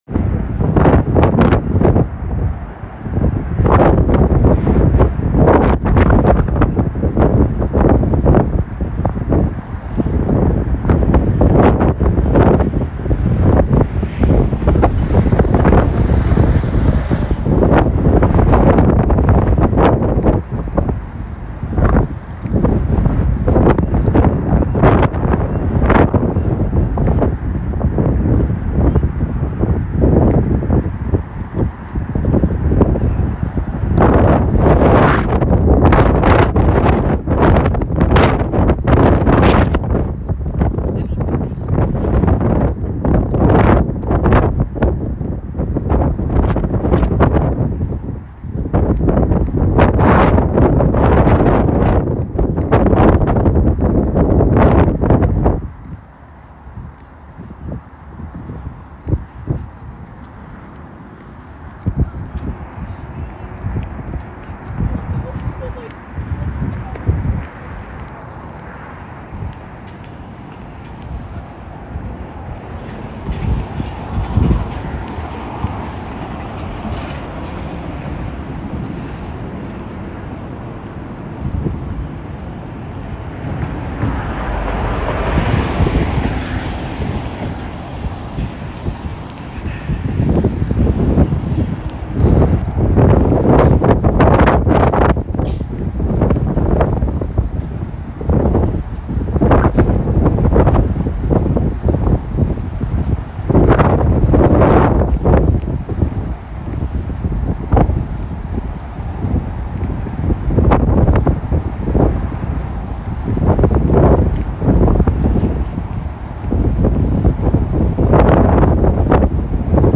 A walk along the Forth Road Bridge overlooking the UNESCO Heritage Forth Bridge just outside Edinburgh. You can hear a lot of wind, road works, tugboats, jingle of lovelocks, and a cargo ship. 19 December 2024.